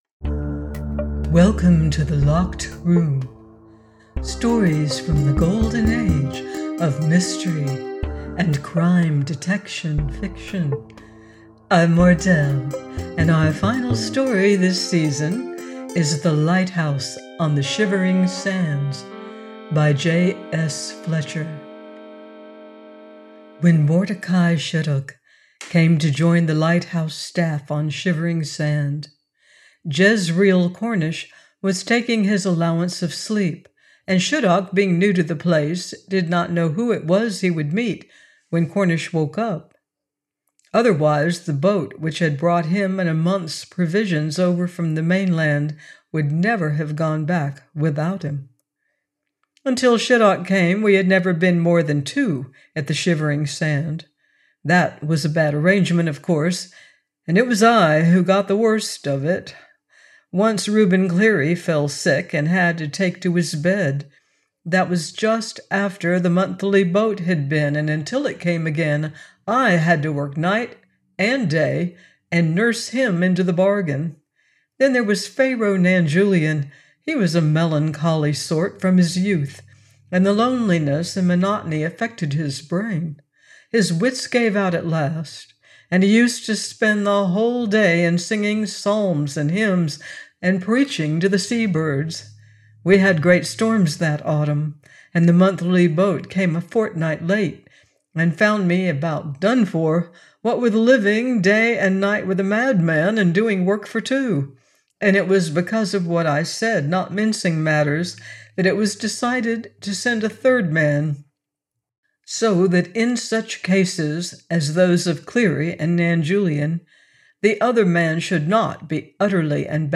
A new lighthouse keeper finds himself trapped with his old enemy. - AUDIOBOOK -